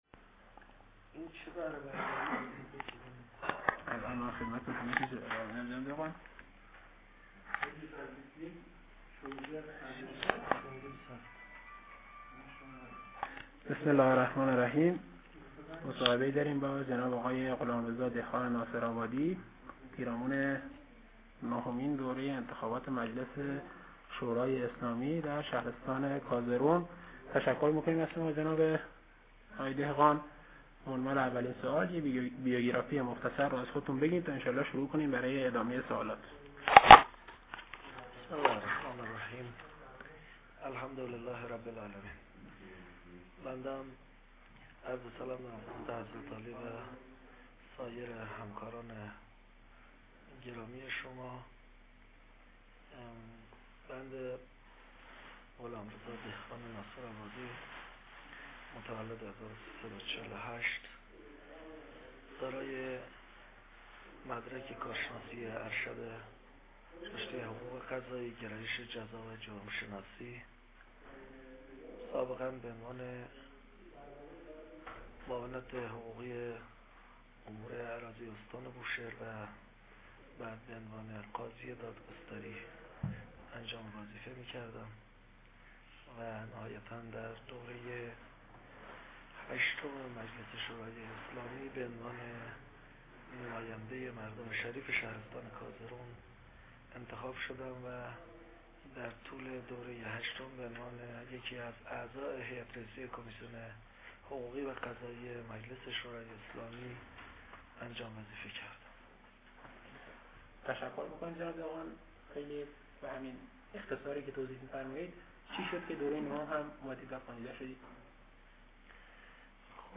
فایل صوتی: مصاحبه کازرون نما با